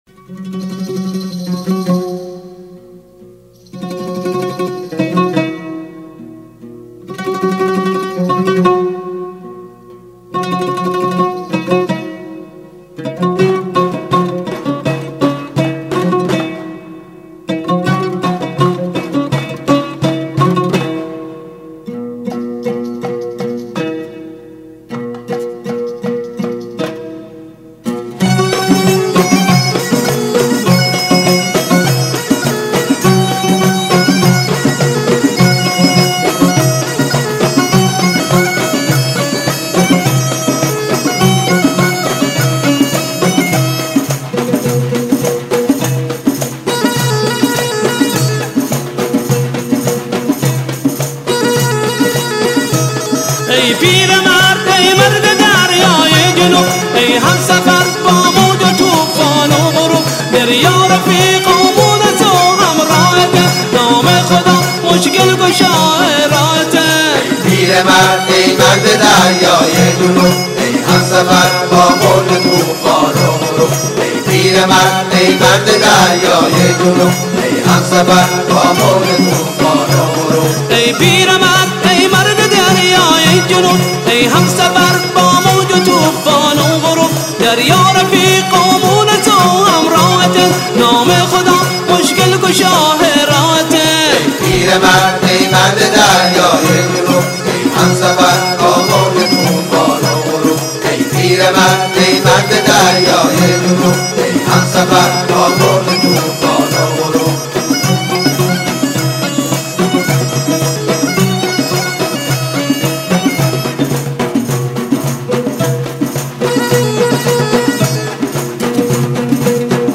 мусиқӣ